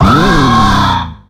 Cri de Corboss dans Pokémon X et Y.